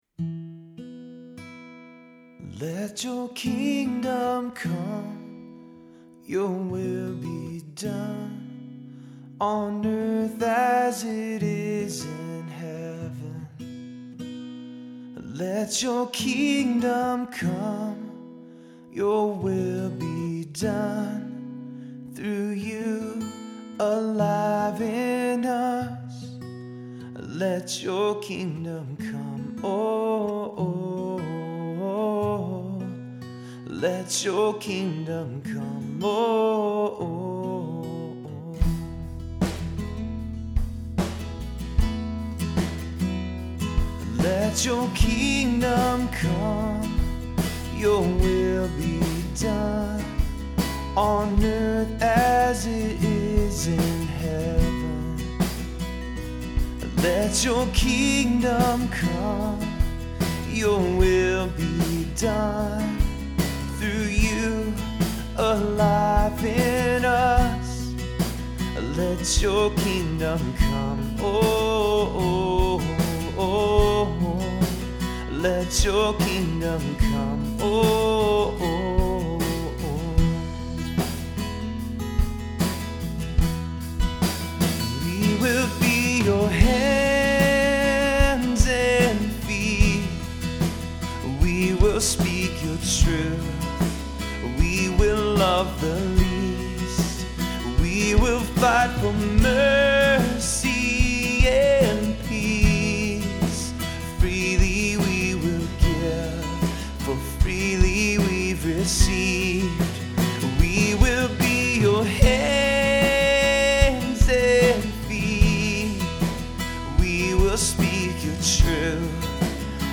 This is not a final version of the song. It is just a demo that I recorded today. So far I am playing all of the instruments and I put a rough mix together, but it is not at all finished.
let-your-kingdom-come-demo-mix1.mp3